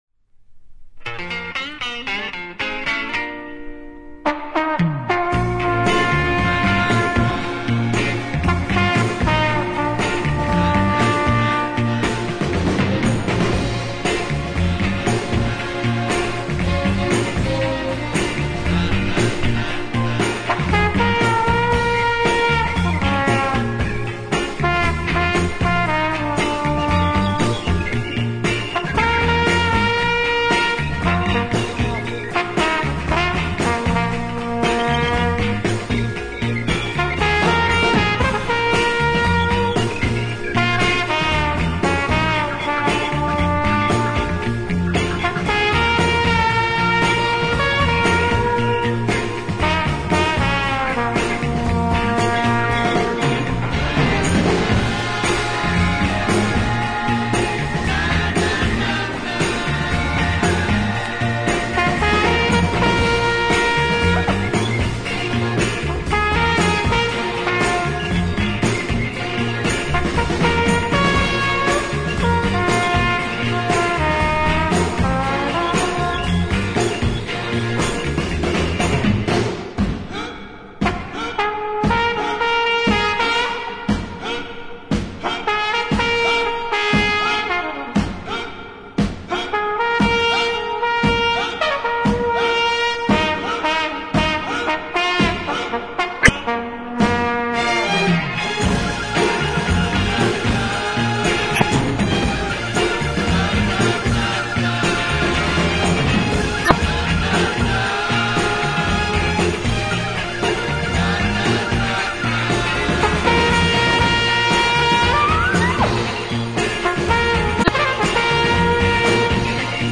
flugelhorn